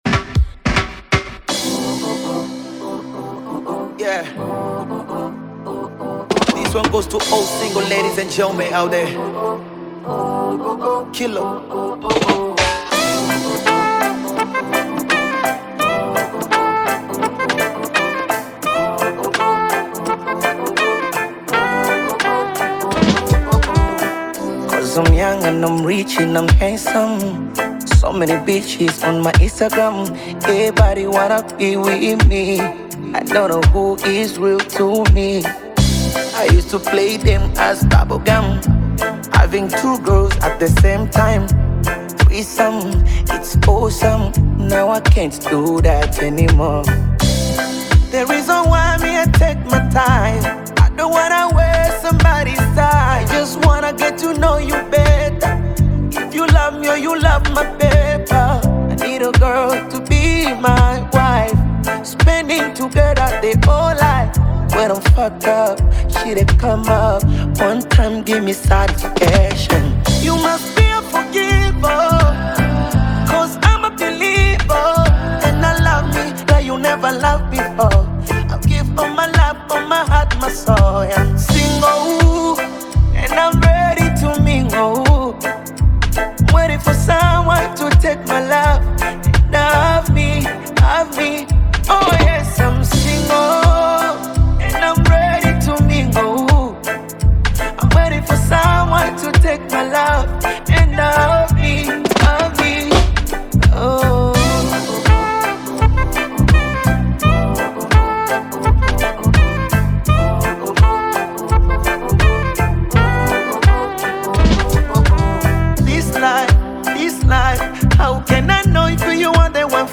Bongo Flava musician